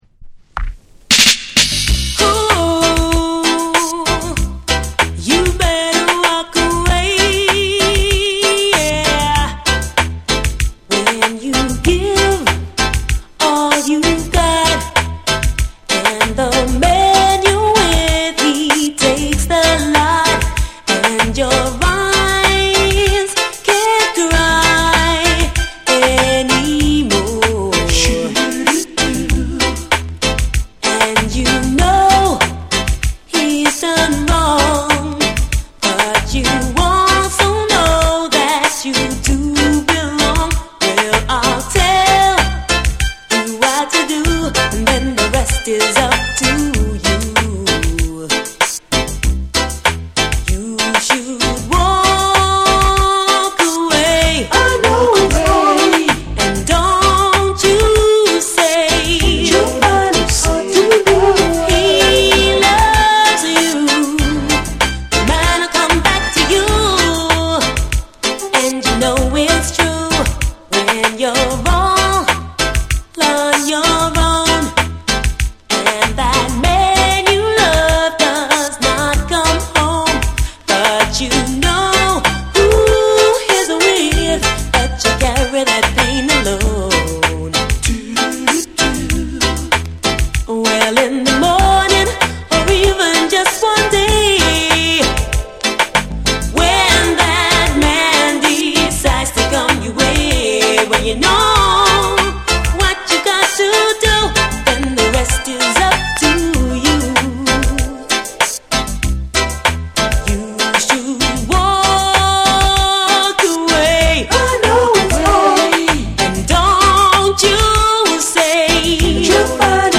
UKラヴァーズ・シーンを代表する女性シンガー